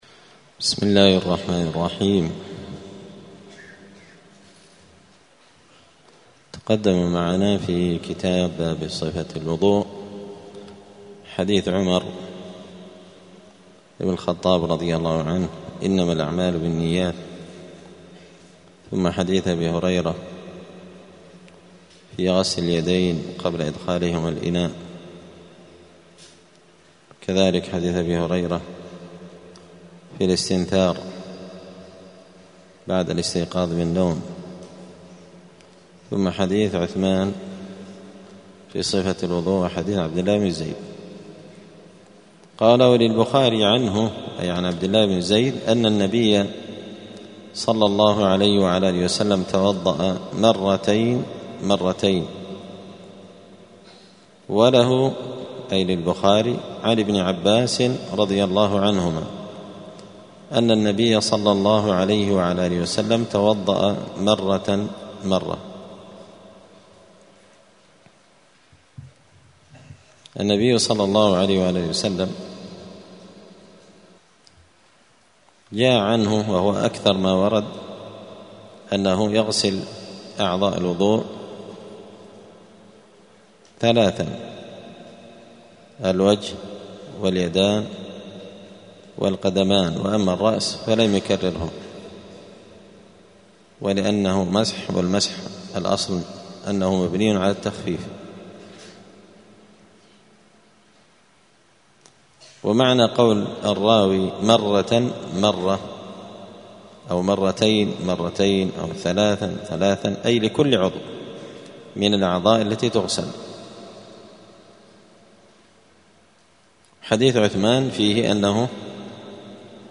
دار الحديث السلفية بمسجد الفرقان قشن المهرة اليمن
*الدرس السادس والثلاثون [36] {باب صفة الوضوء عدد مرات الغسل في الوضوء}*